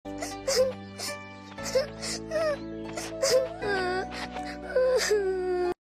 Kai Lan Crying - Bouton d'effet sonore